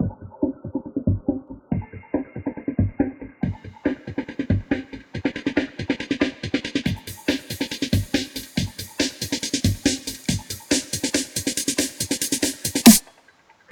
Drum Buildup.wav